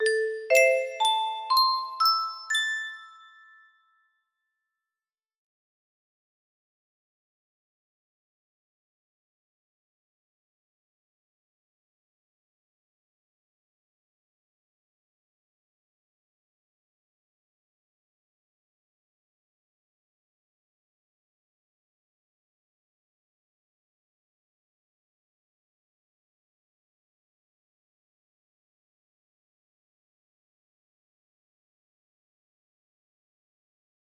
a music box melody
Grand Illusions 30 (F scale)